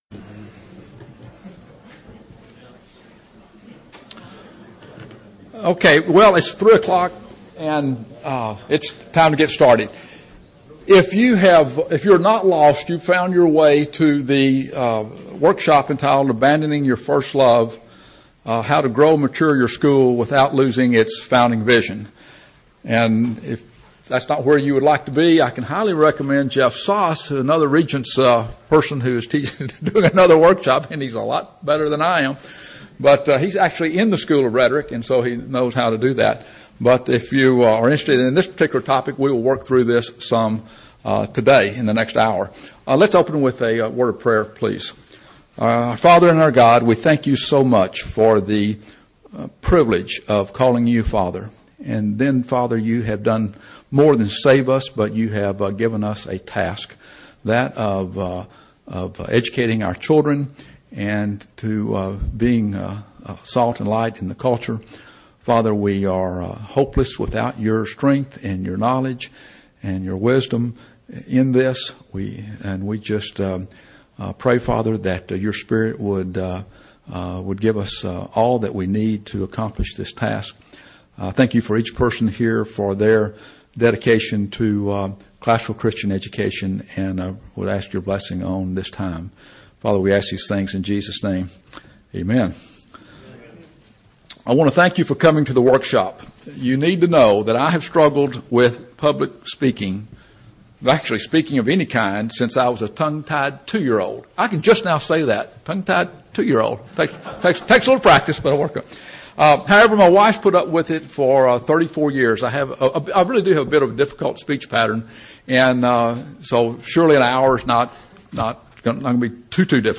2008 Workshop Talk | 0:57:51 | Leadership & Strategic
The challenges to mission preservation Action plan Speaker Additional Materials The Association of Classical & Christian Schools presents Repairing the Ruins, the ACCS annual conference, copyright ACCS.